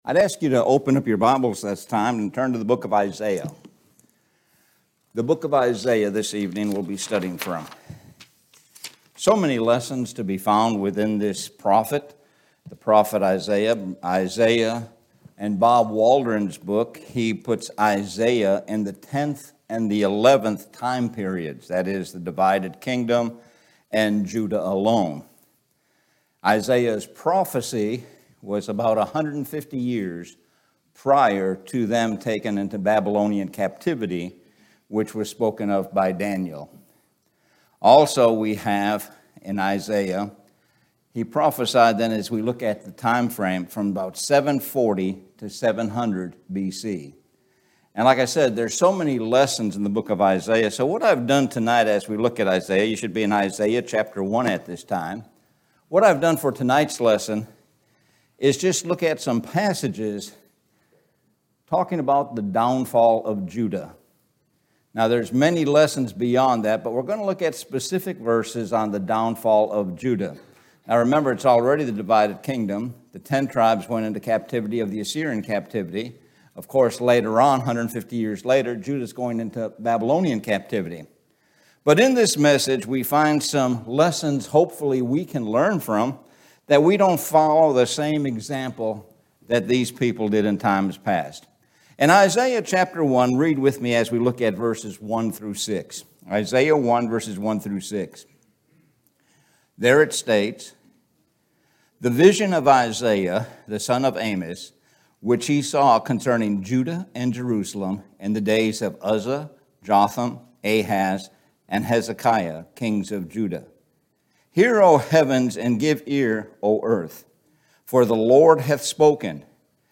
Sun PM Sermon – downfall of Judah